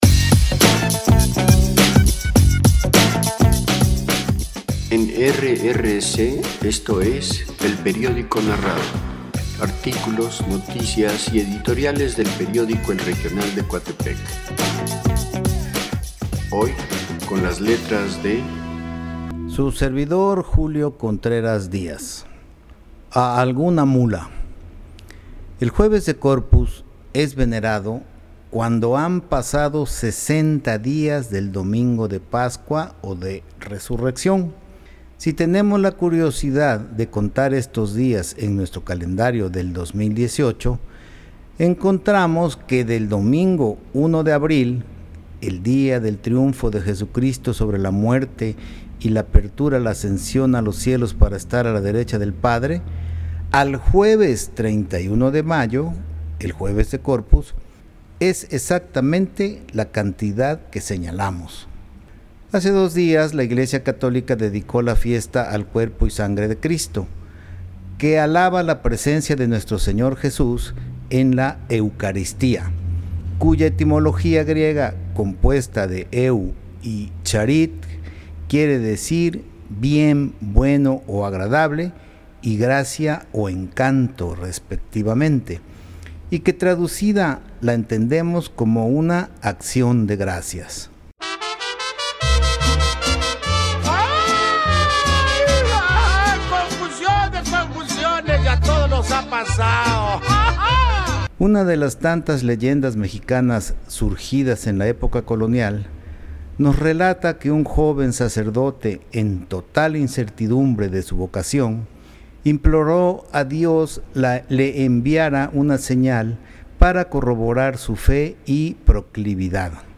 Jueves-de-Corpus.mp3